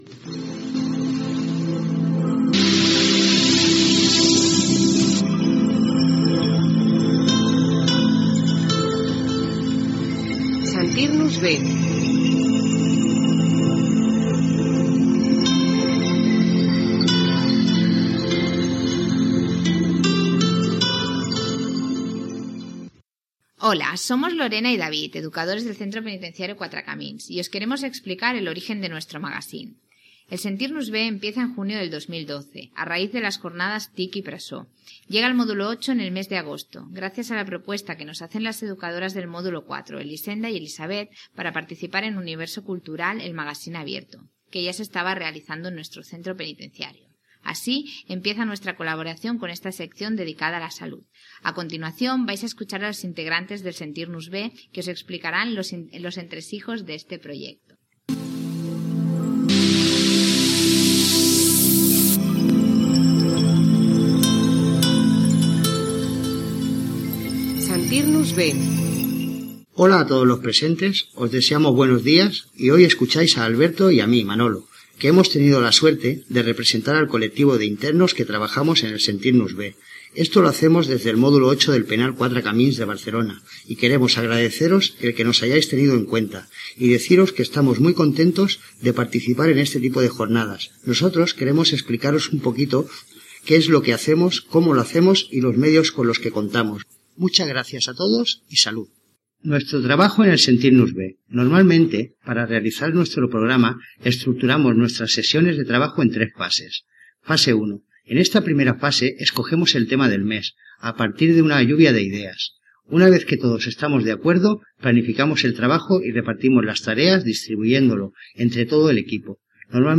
Careta, explicació del projecte, els interns expliquen com produeixen i fan el programa i quins sentiments tenen en fer-lo.